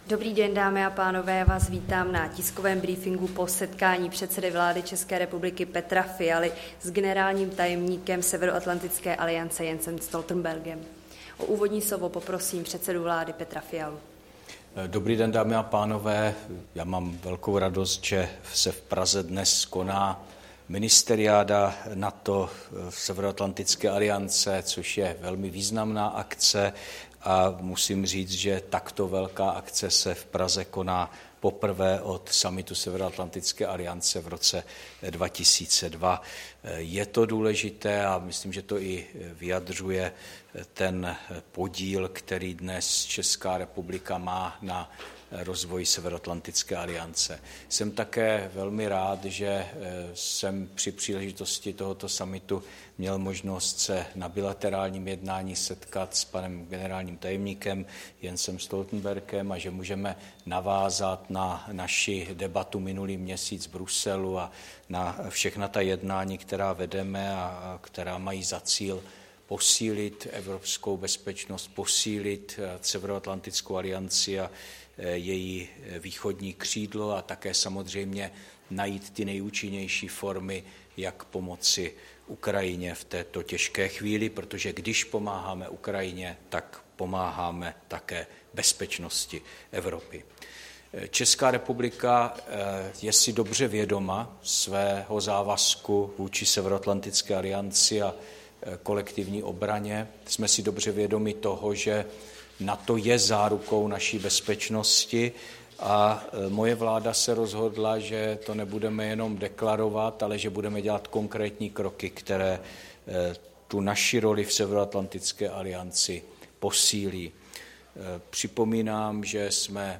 Tisková konference premiéra Fialy s generálním tajemníkem NATO Jensem Stoltenbergem (v originále), 30. května 2024